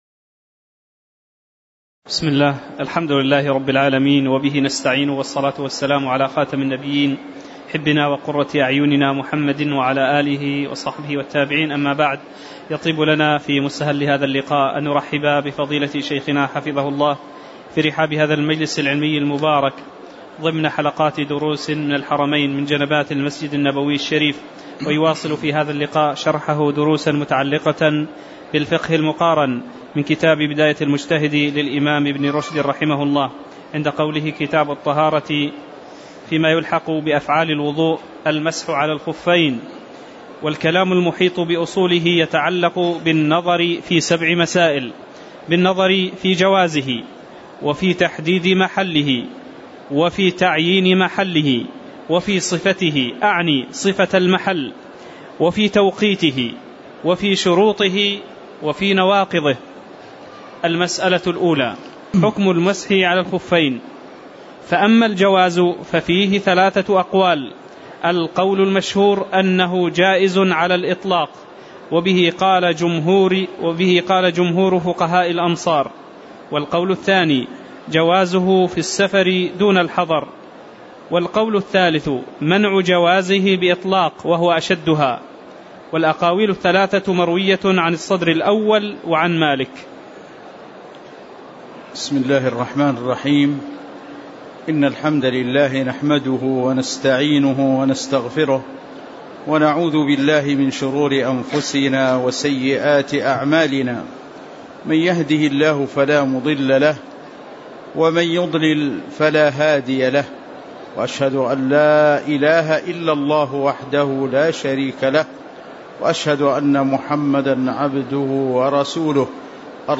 تاريخ النشر ١٦ رجب ١٤٣٩ هـ المكان: المسجد النبوي الشيخ